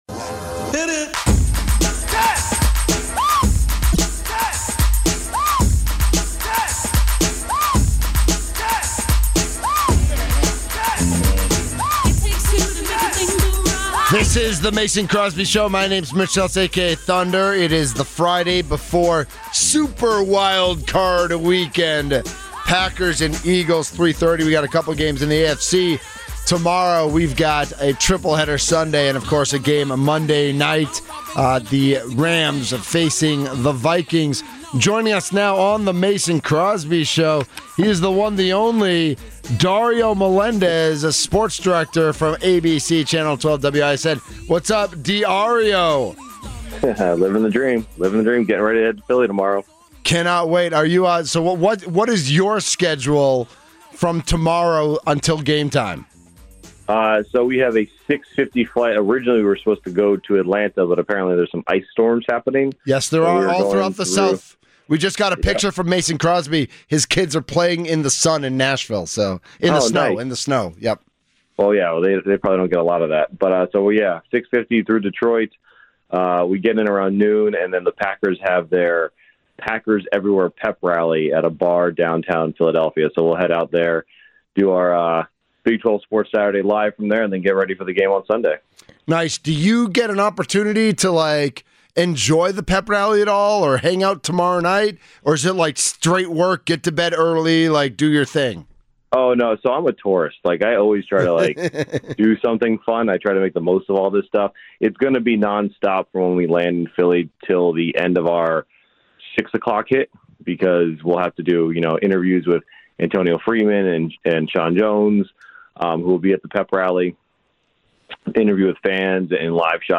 Also hear players coaches and our Football insiders.